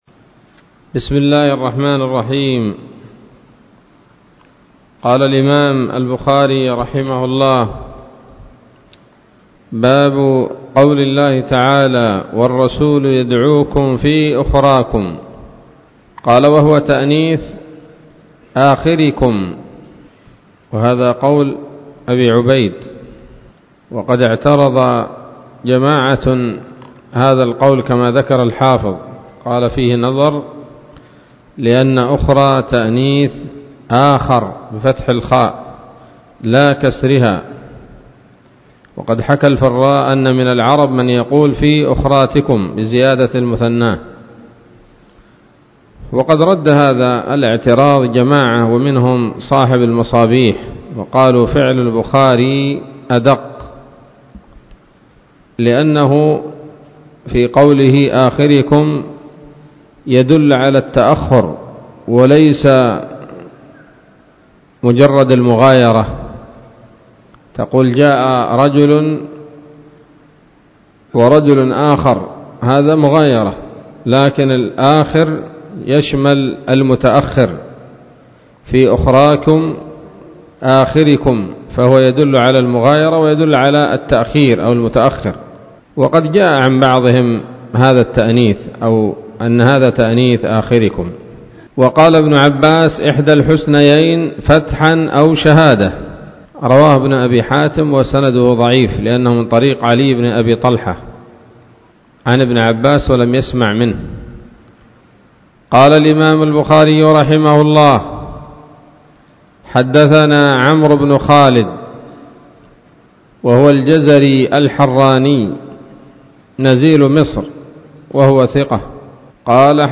الدرس الرابع والخمسون من كتاب التفسير من صحيح الإمام البخاري